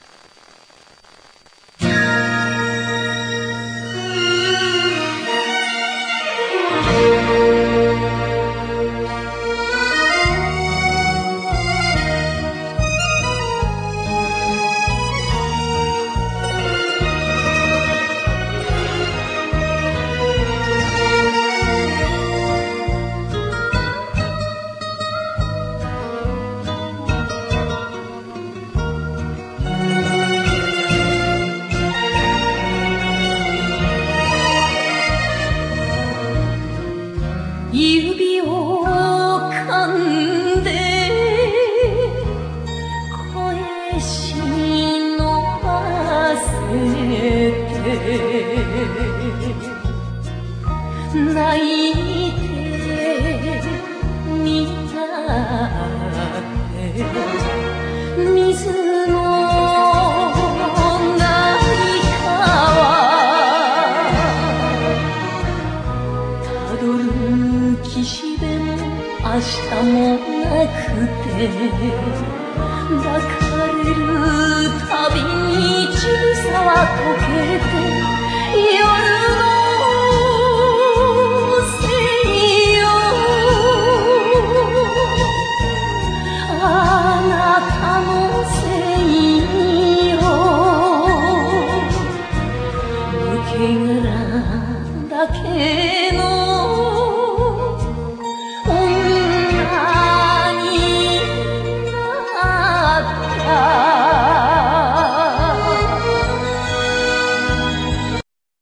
enka songs of rather conservative and traditional style